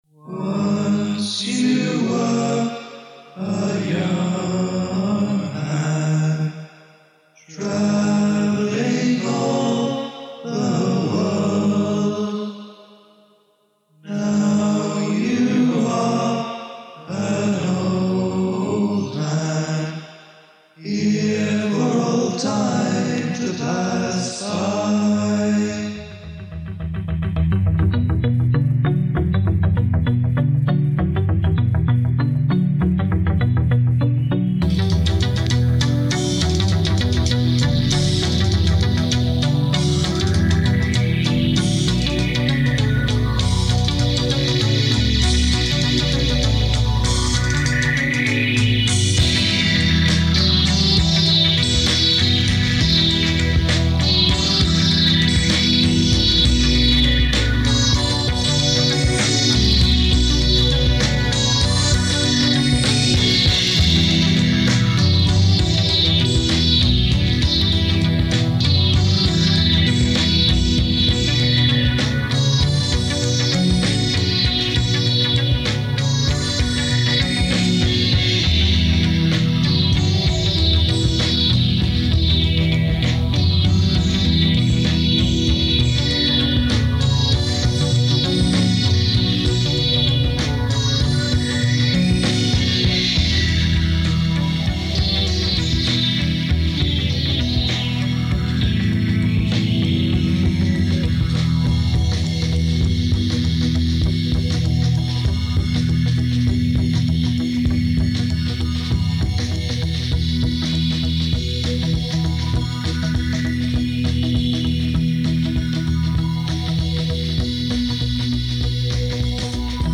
Digitally remastered
Recorded in Dursley, December 1978
Keyboards, Vocals, Guitars